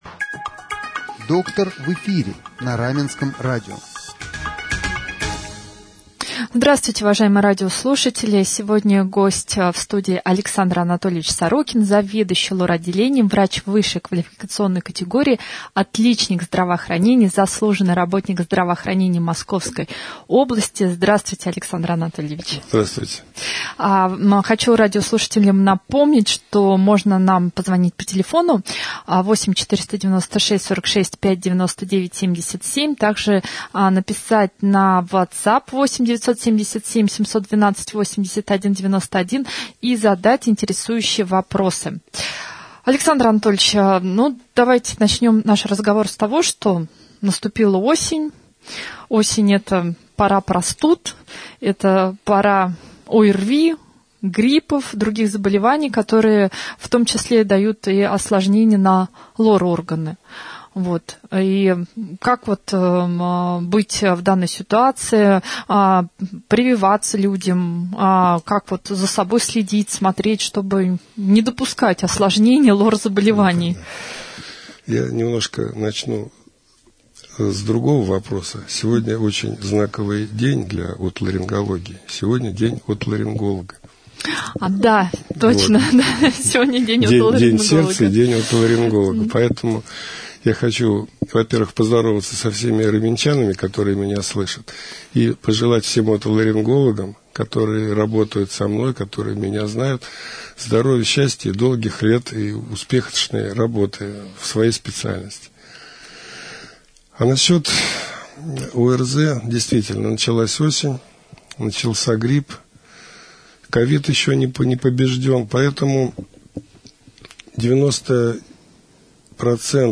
гостем прямого эфира на Раменском радио